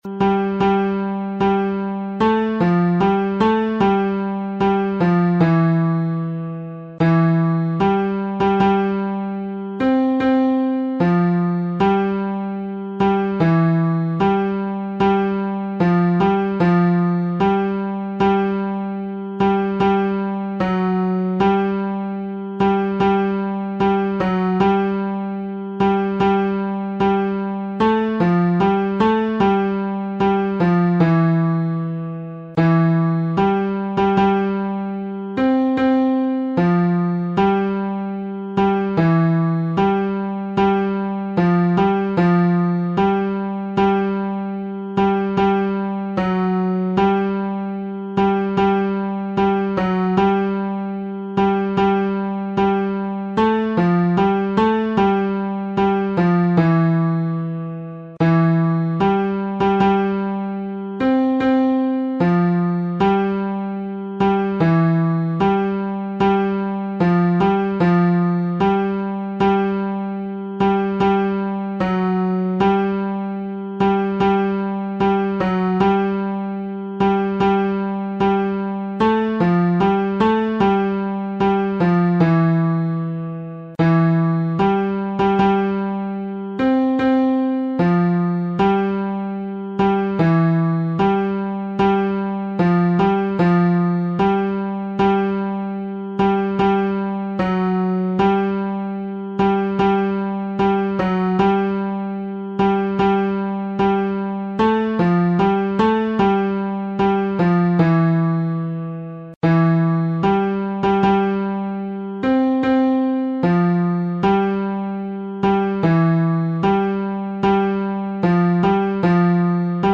伴奏
男高